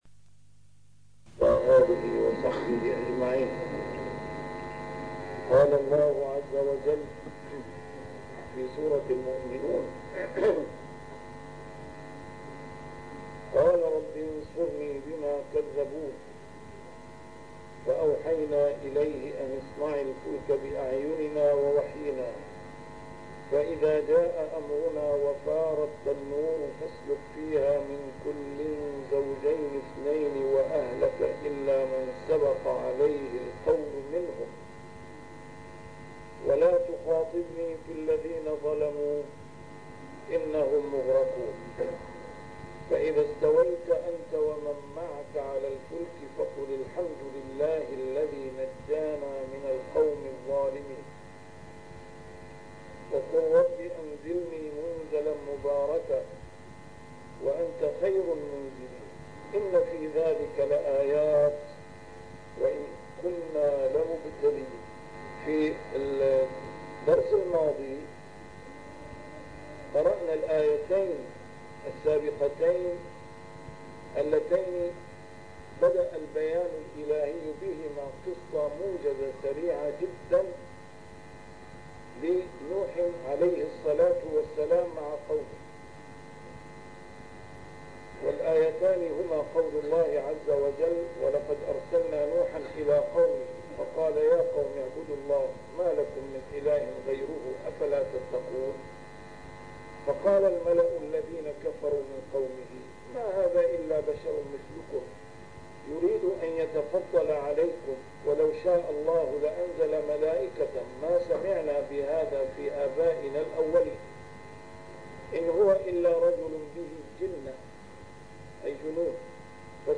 A MARTYR SCHOLAR: IMAM MUHAMMAD SAEED RAMADAN AL-BOUTI - الدروس العلمية - تفسير القرآن الكريم - تسجيل قديم - الدرس 152: المؤمنون 026-030